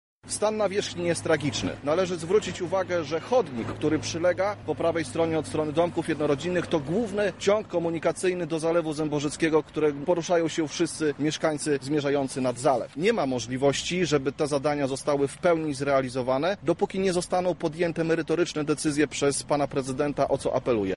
–mówi Piotr Popiel radny Lublina